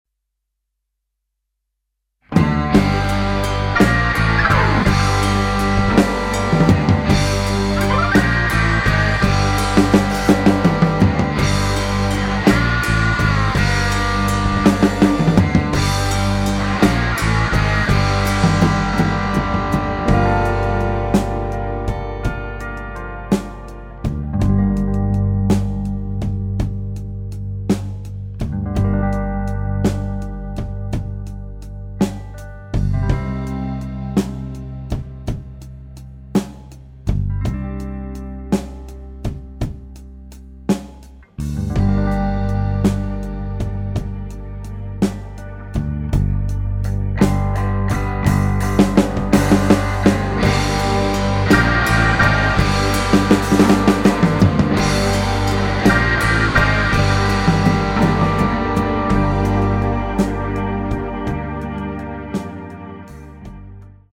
Gitarre
Bass
Schlagzeug
Keyboard
Genre: Rock and Roll
Stereo